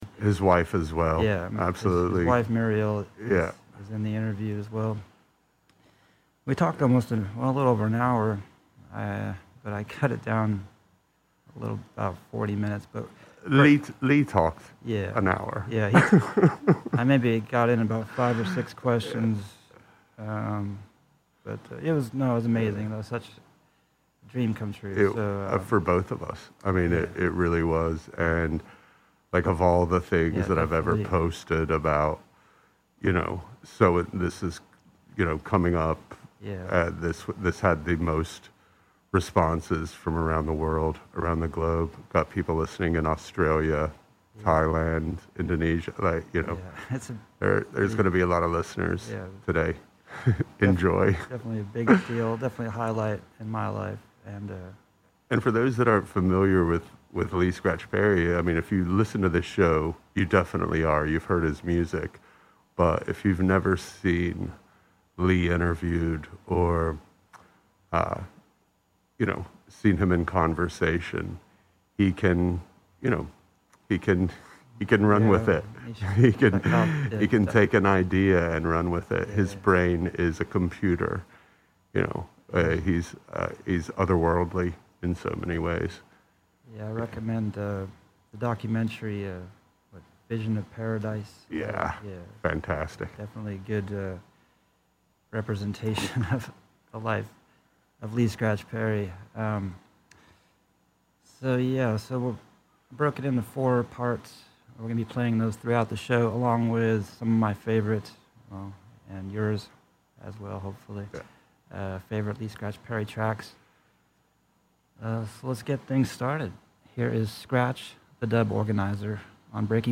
Lee Scratch Perry Interview on WTSQ (Part 1 of 2)